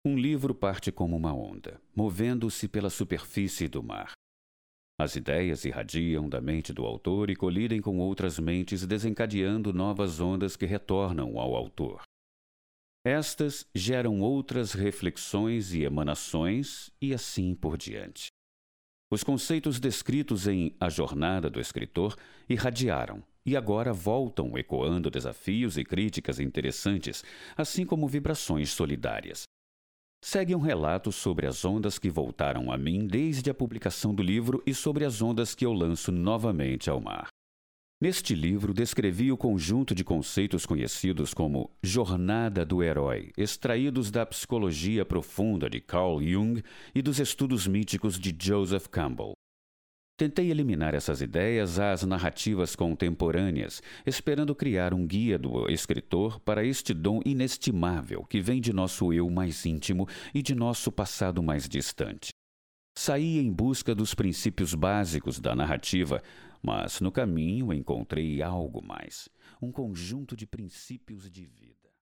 Masculino
Locução - Painel de Cinema e TV
Voz Varejo